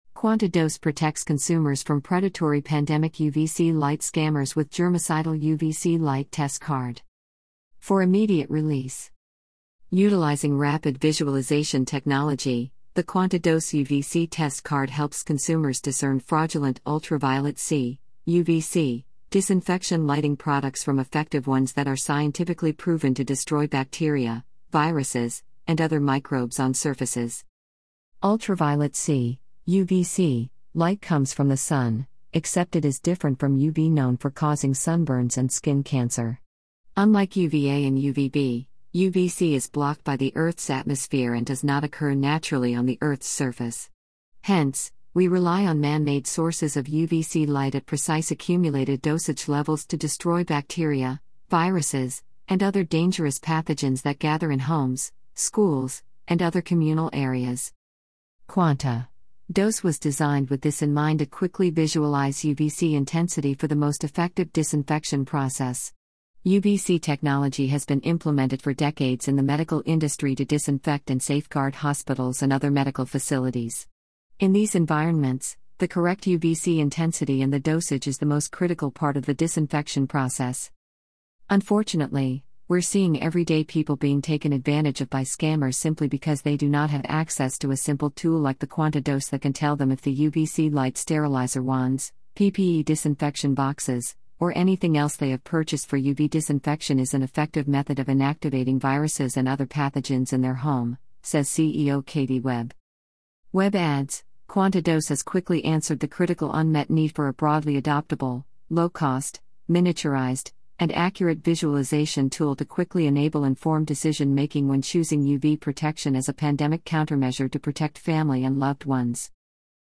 AI Reader